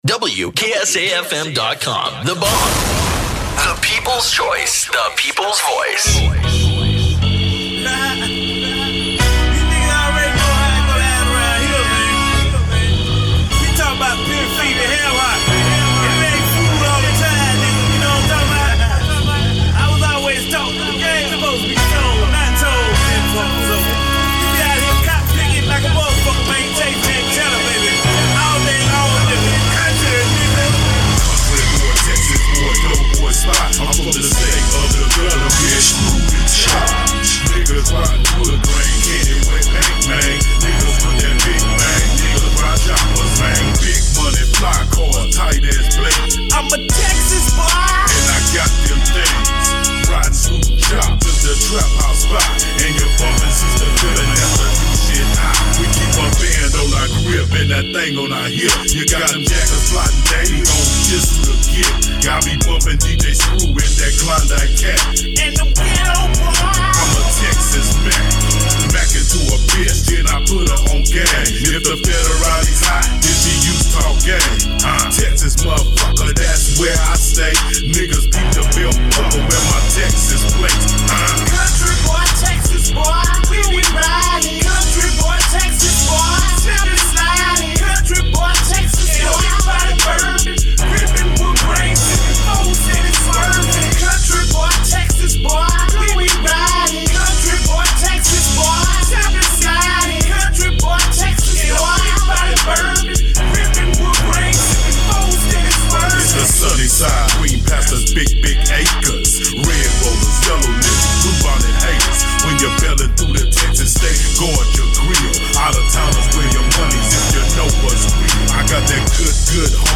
DJ Mixes
HIP HOP